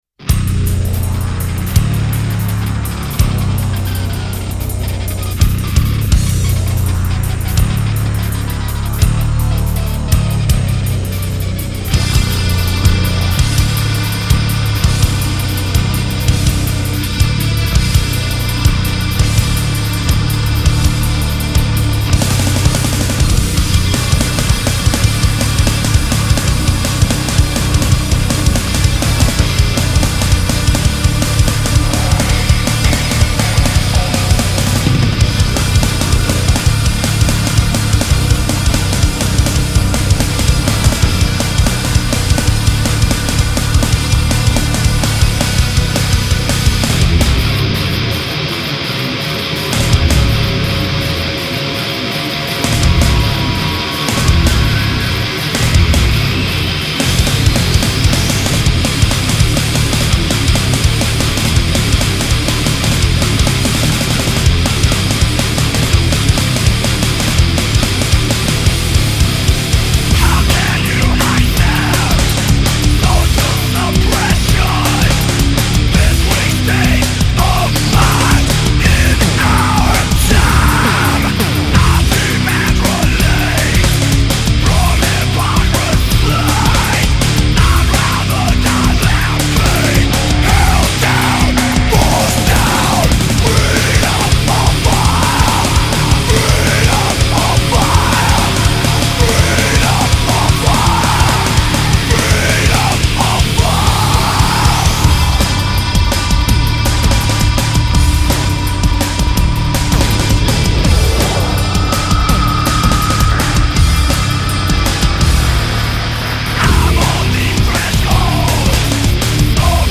ici les ours des cavernes se déchaînent :